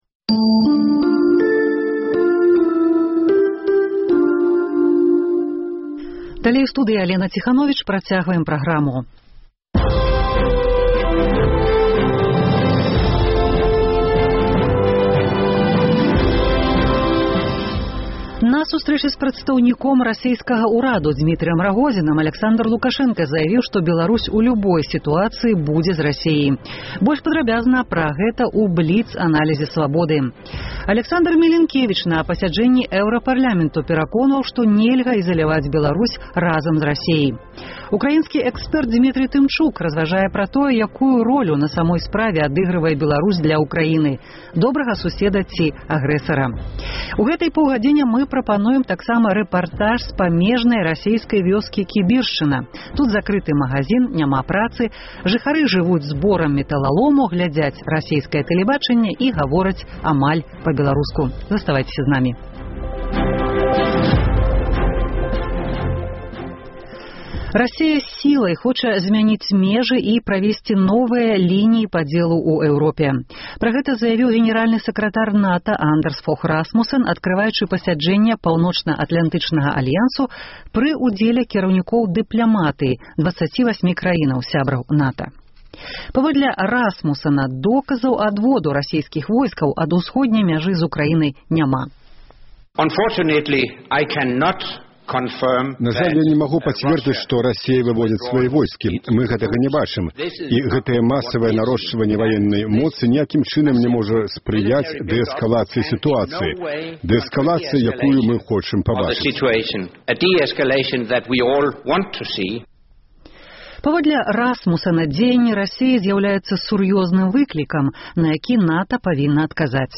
Рэпартаж з памежнай расейскай вёскі Кібіршчына: закрыты магазін, жыхары жывуць зборам мэталалому, глядзяць расейскае ТВ і гавораць амаль па-беларуску.